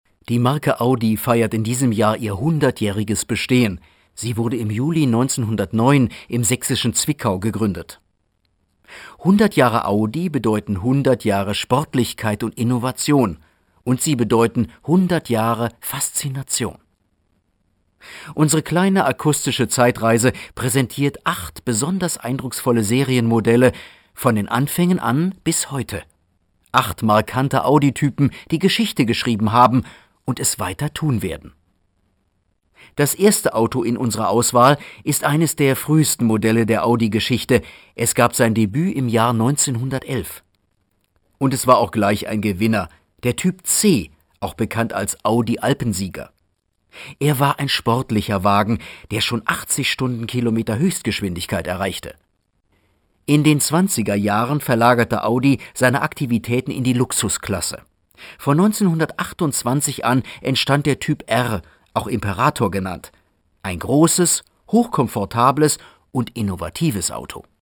deutscher Profi-Sprecher. Breites Spektrum von sachlich bis ausgeflippt (Trickstimme).
Sprechprobe: Werbung (Muttersprache):